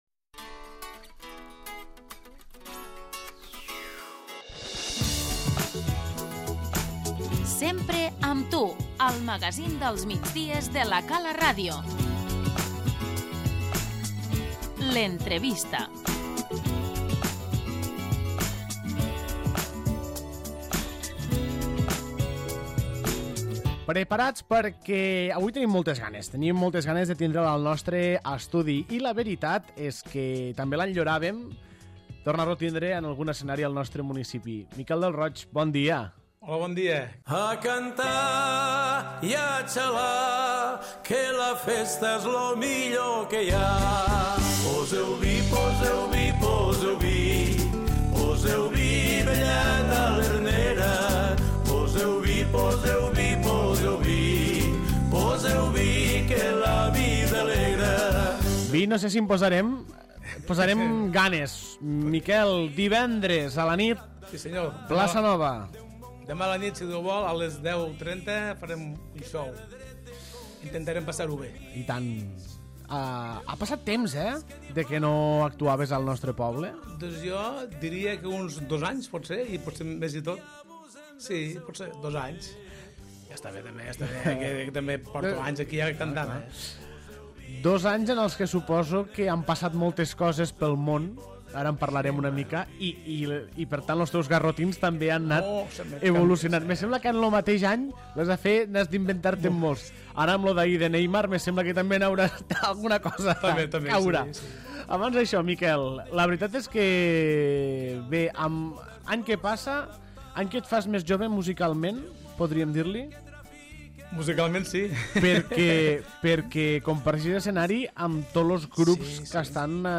L'entrevista